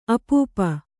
♪ apūpa